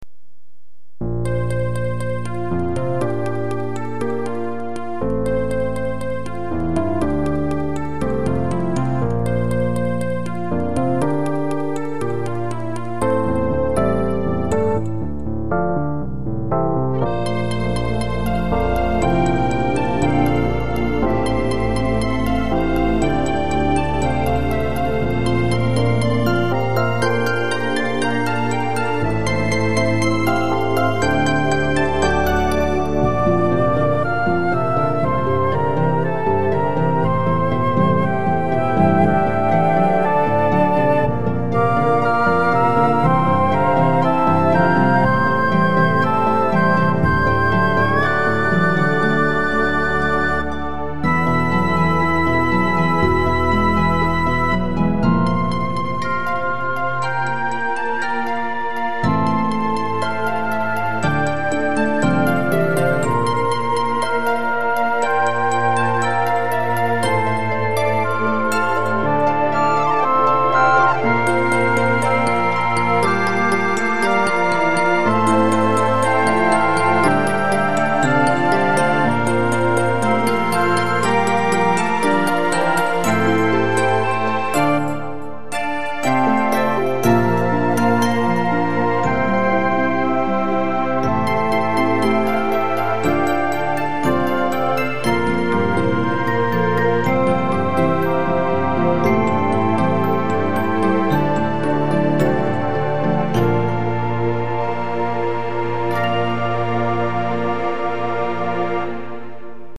覚えやすいメロディとシンプルなアレンジですが、飽きる。